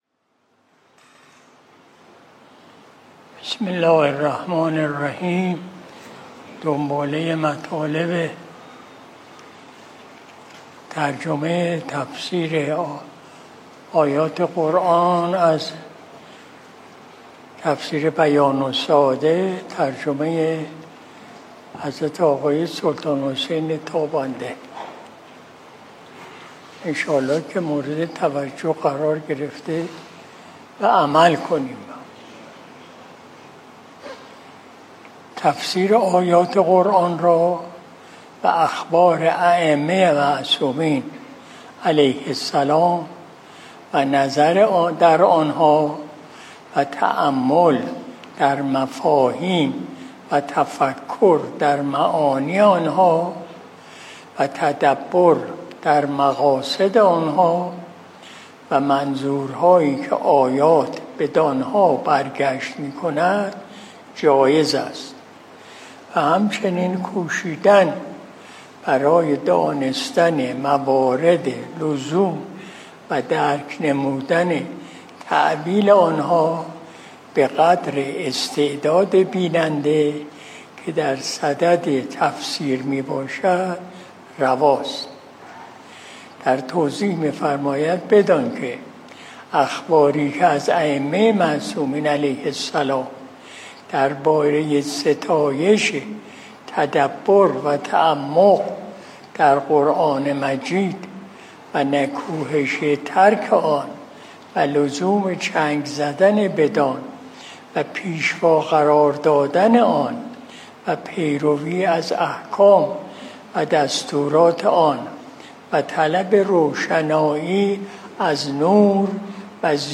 مجلس شب جمعه ۲۶ مرداد ماه ۱۴۰۲ شمسی